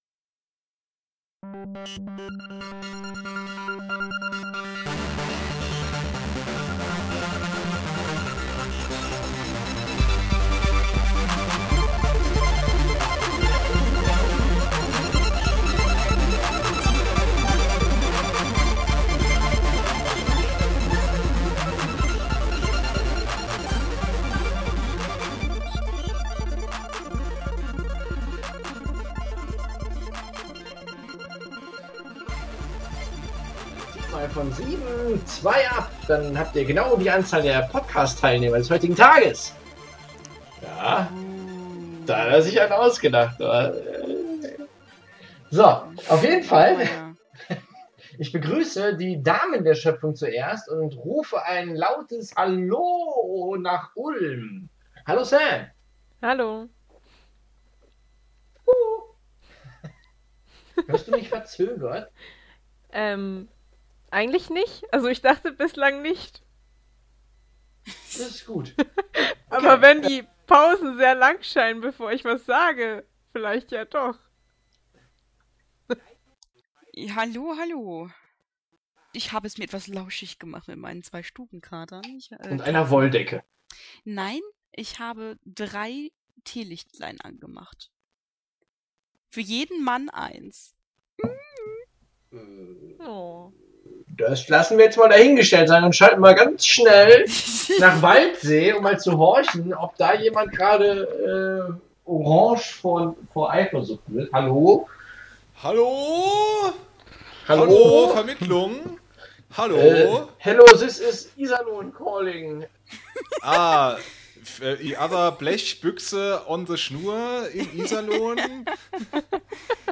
Um die Folge noch zu retten, musste hier und da geschraubt und geschnitten werden.